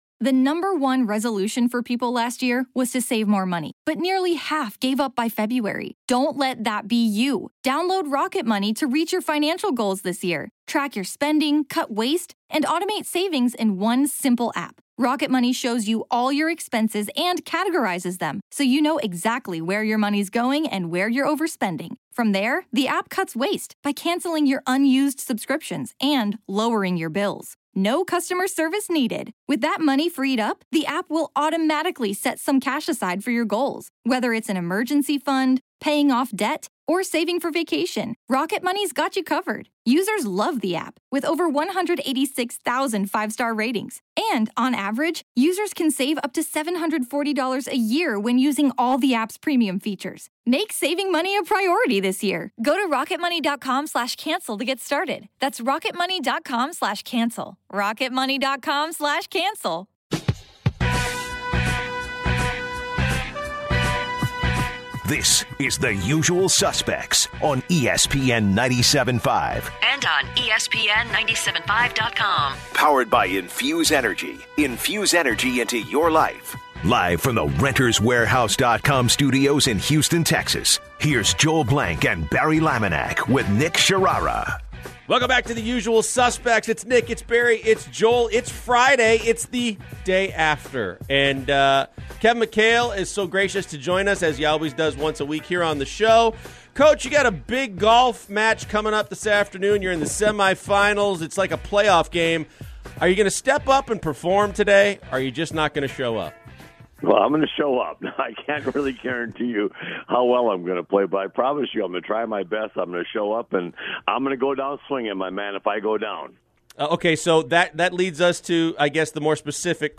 05/12/2017 Kevin McHale Interview